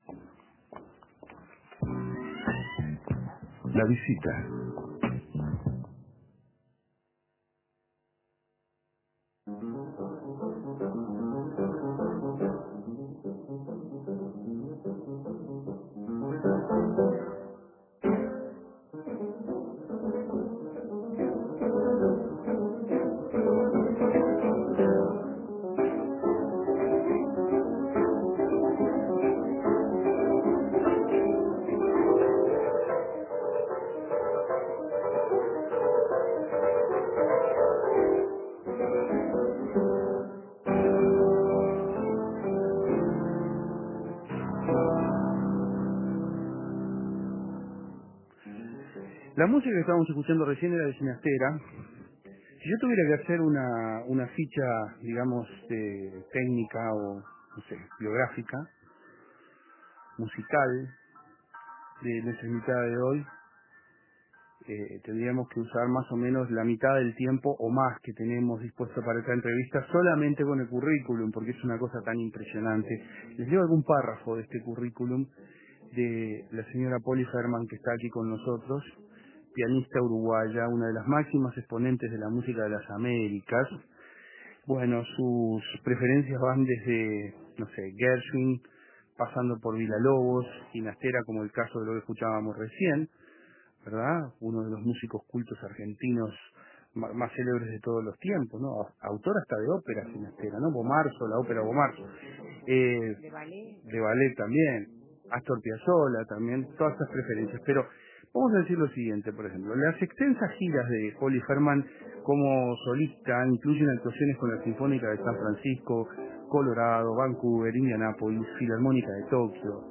En esta entrevista